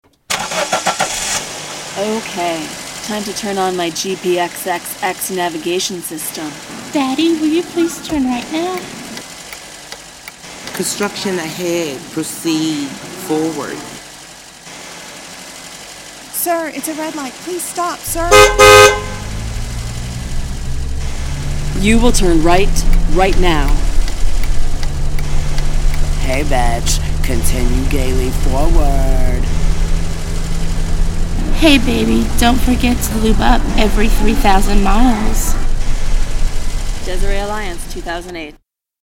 Chicago, July 08 @ Desiree Alliance